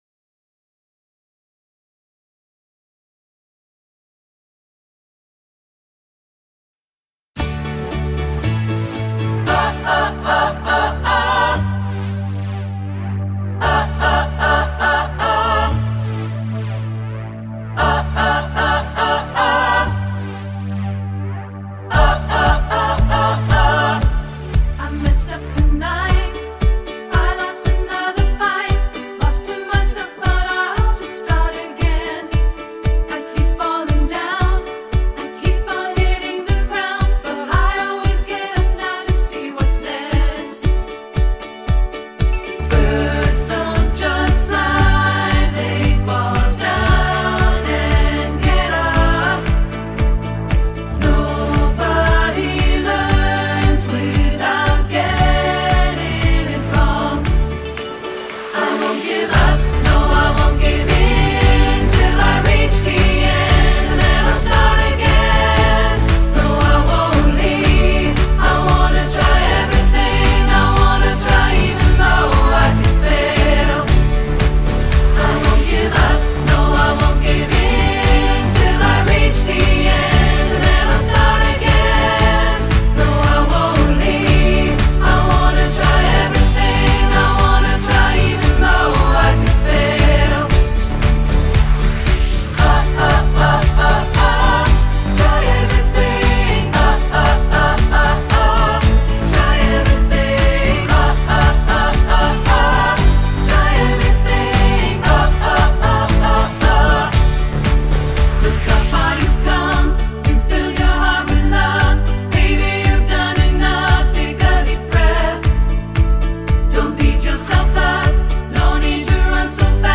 SATB (Arrangement)